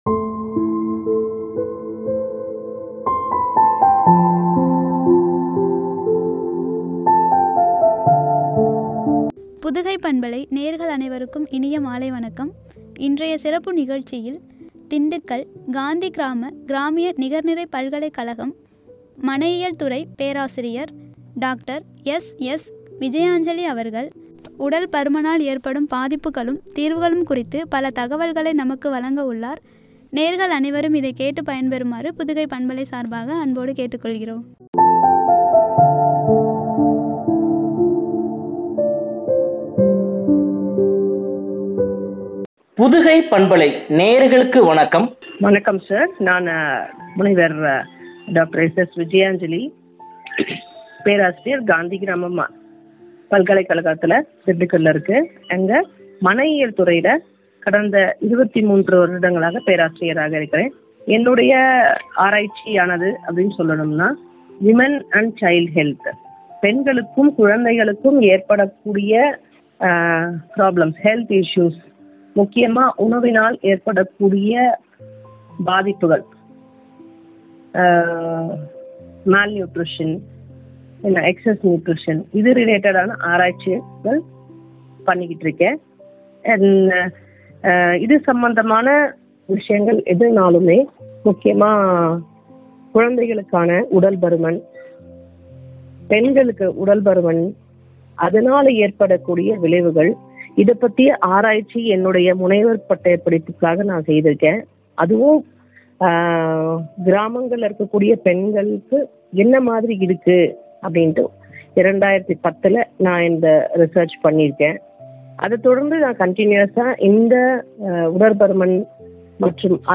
தீர்வுகளும்” குறித்து வழங்கிய உரையாடல்.